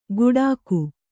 ♪ guḍāku